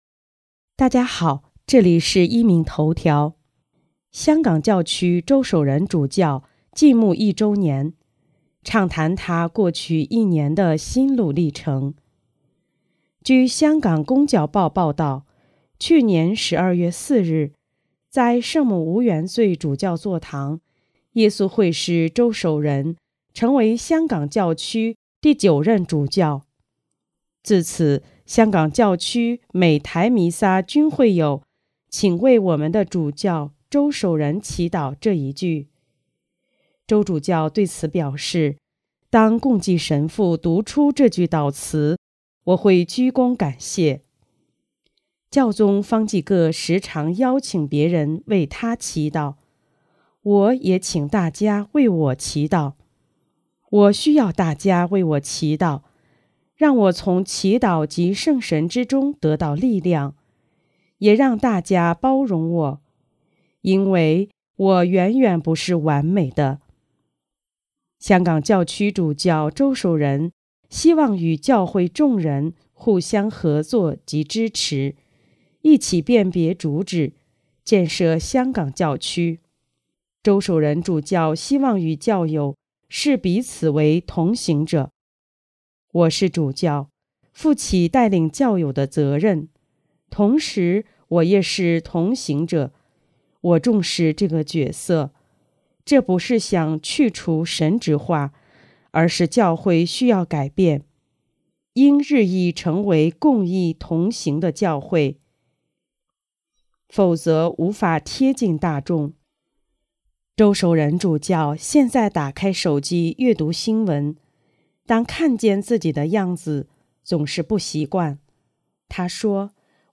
【壹明头条】|周守仁主教晋牧一周年专访